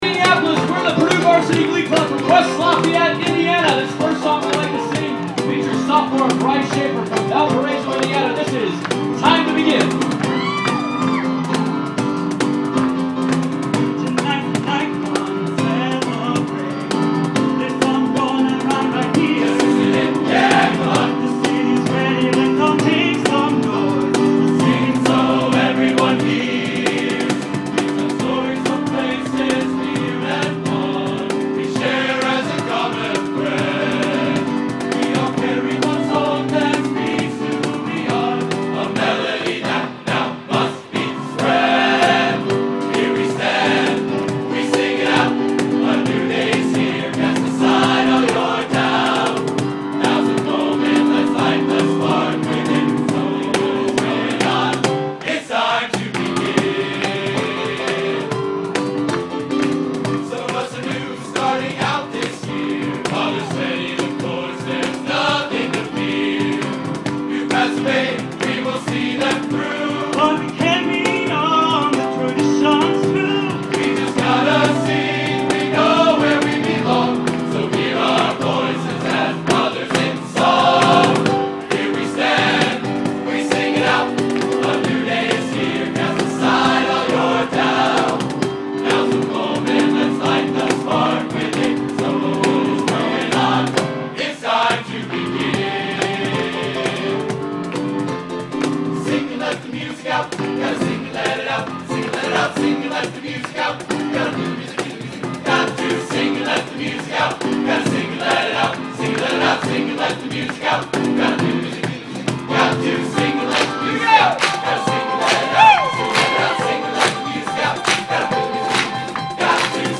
performs in Super Bowl Village